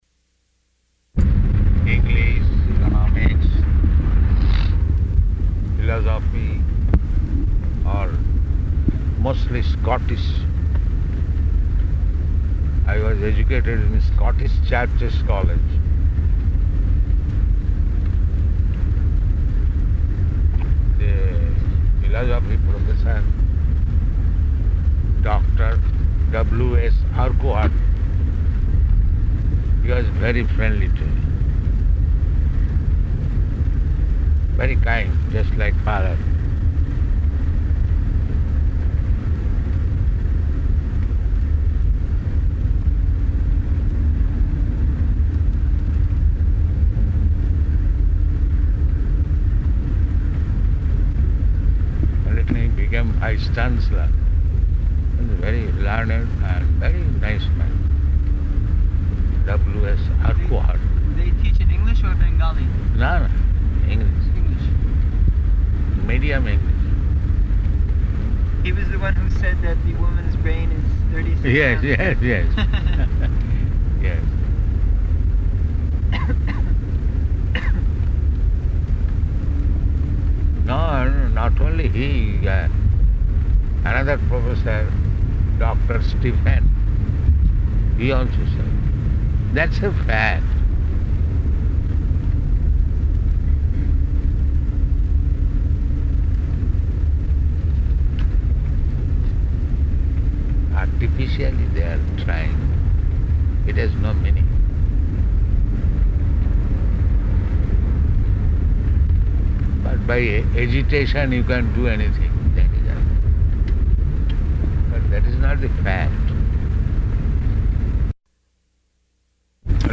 Morning Walk --:-- --:-- Type: Walk Dated: October 6th 1975 Location: Durban Audio file: 751006MW.DUR.mp3 [in car] Prabhupāda: English, economics, philosophy or..., mostly Scottish...